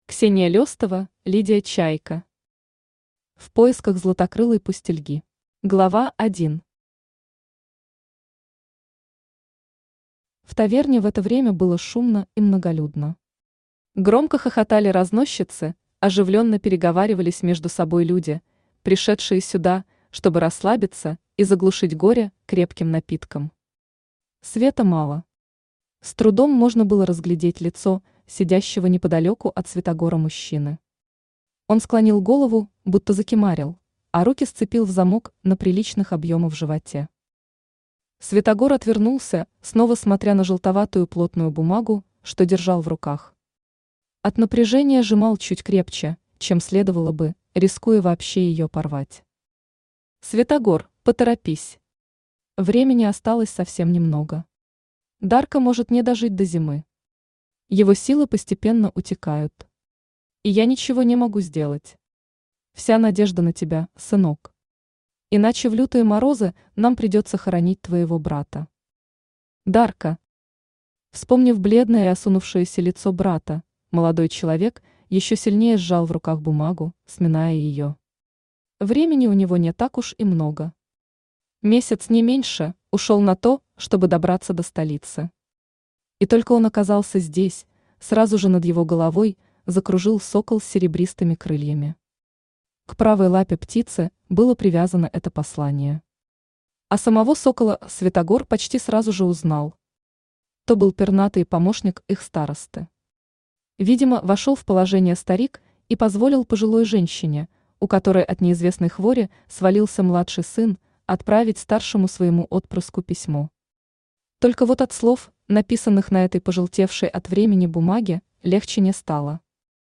Аудиокнига В поисках златокрылой пустельги | Библиотека аудиокниг
Aудиокнига В поисках златокрылой пустельги Автор Ксения Алексеевна Лестова Читает аудиокнигу Авточтец ЛитРес.